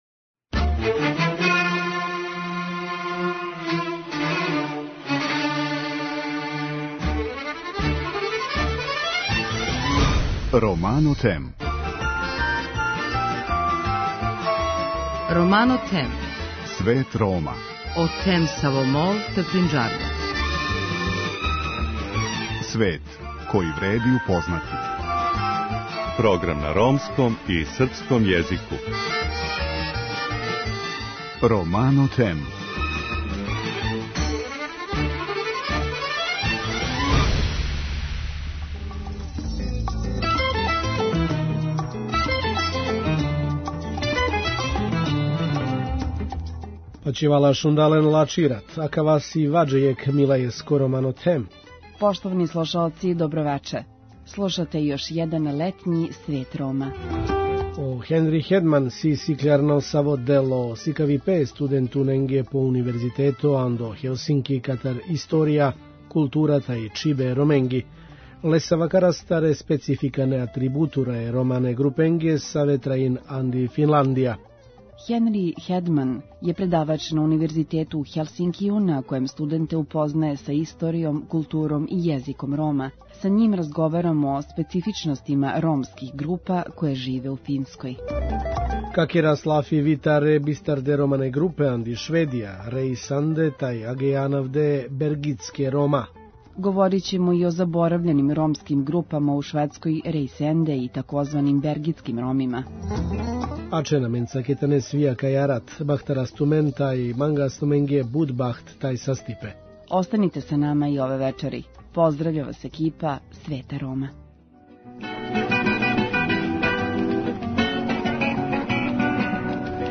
Са њим разговарамо о специфичностима ромских група које живе у Финској.